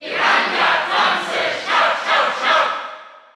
Category: Crowd cheers (SSBU) You cannot overwrite this file.
Piranha_Plant_Cheer_German_SSBU.ogg